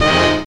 JAZZ STAB 9.wav